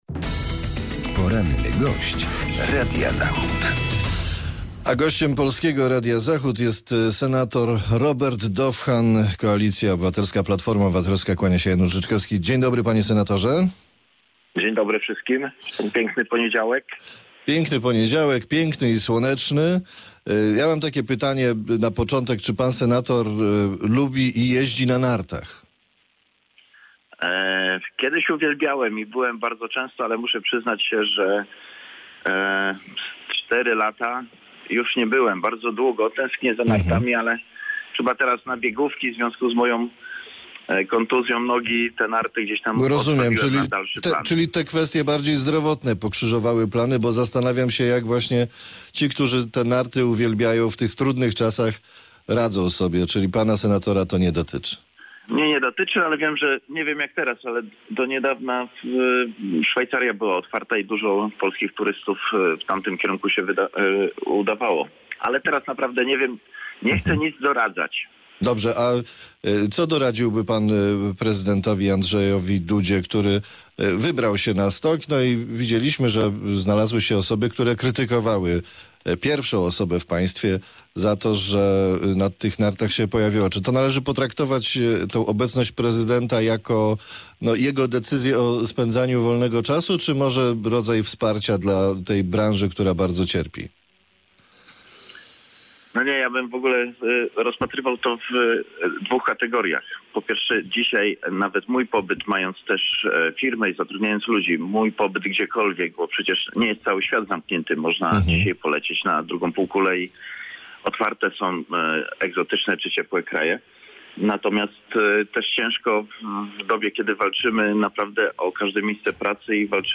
Z senatorem Platformy Obywatelskiej rozmawia